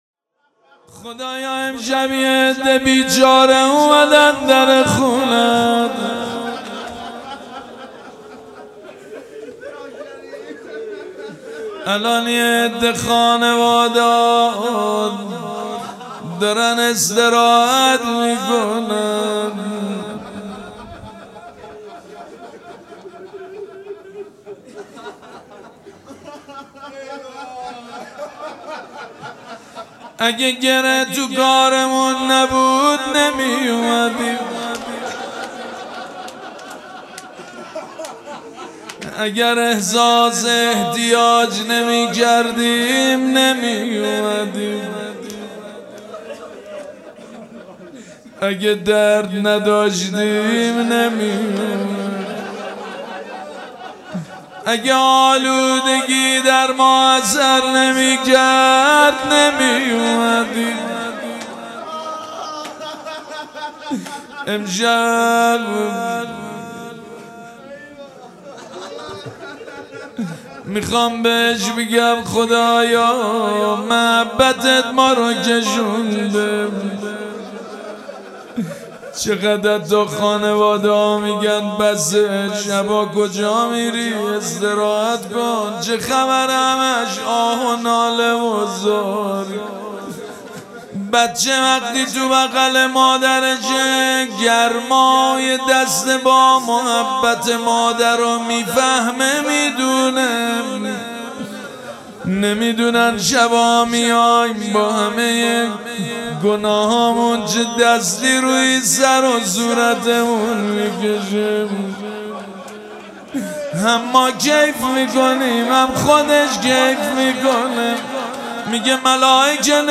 سه شنبه 30 فروردین ۱۴۰۱ | 17 رمضان ۱۴۴۳حسینیه ریحانة‌الحسین (سلام‌الله‌علیها)
مناجات favorite
مداح حاج سید مجید بنی فاطمه